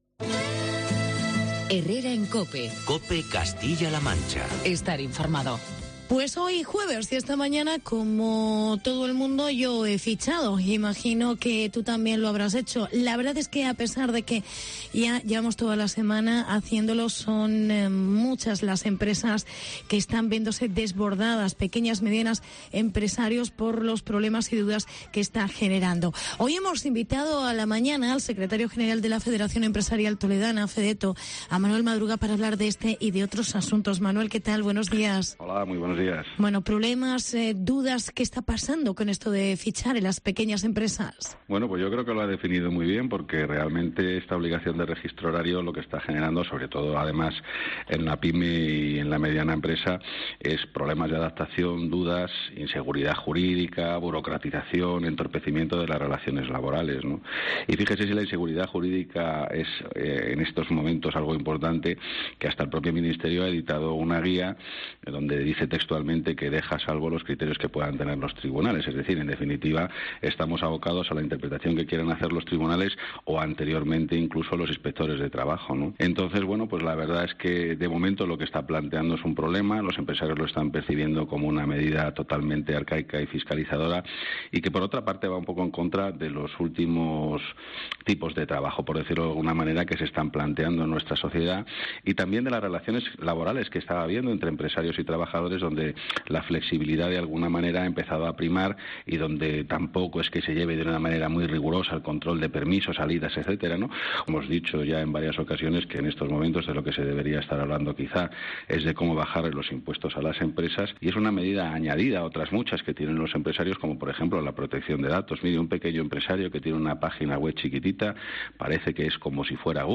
Los empresarios "desbordados" por el registro de la jornada laboral. Entrevista